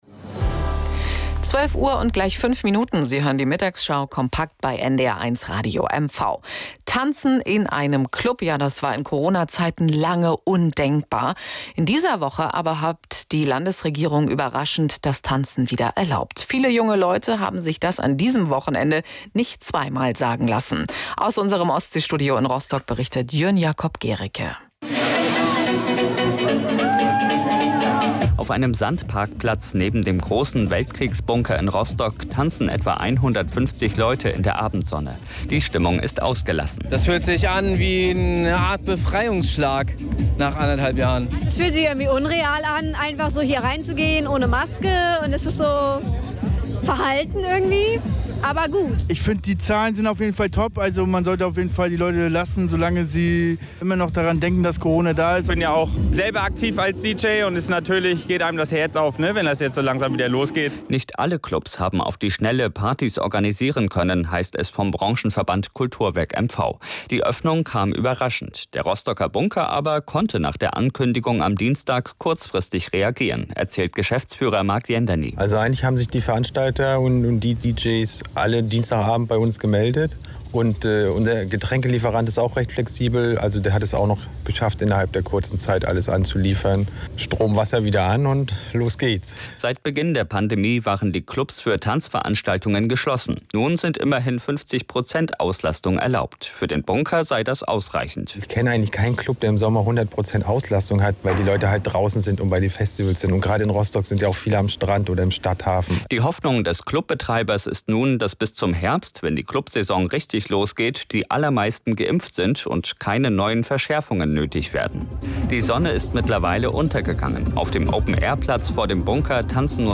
Der NDR war gestern auch da.